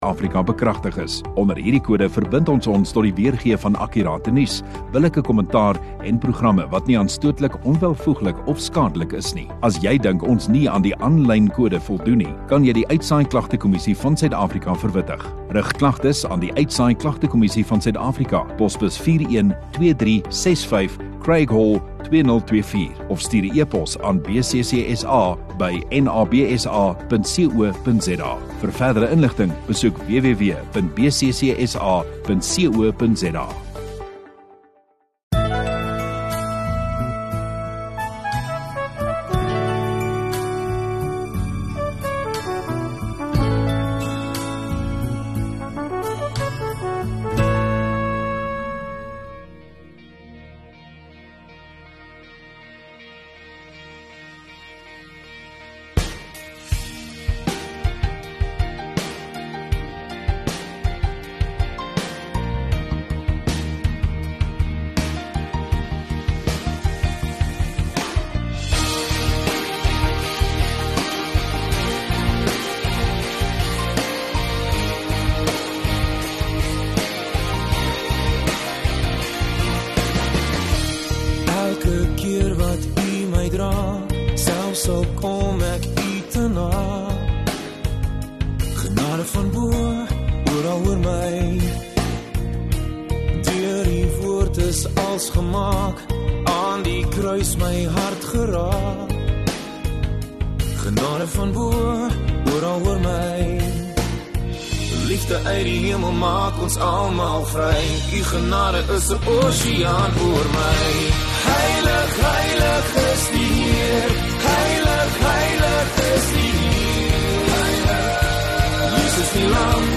11 Apr Saterdag Oggenddiens